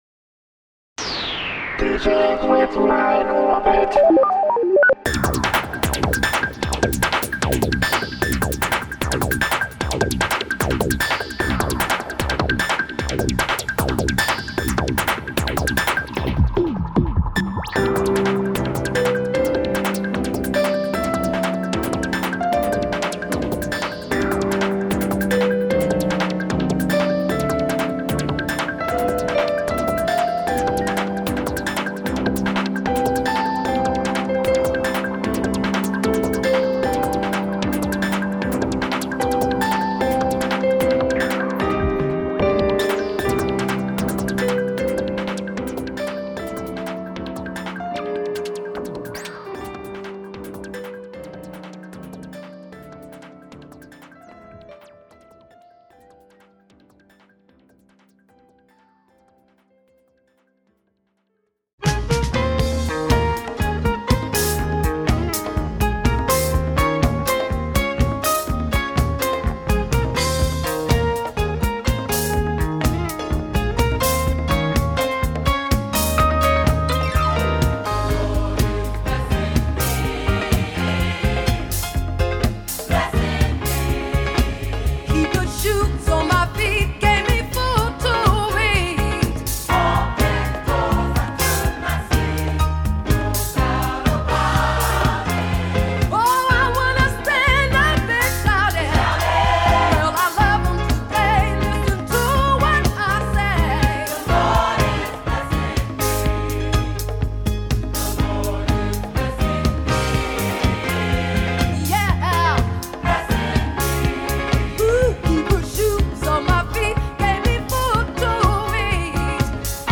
Focused on Contemporary Gospel.